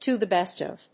アクセントto the bést of…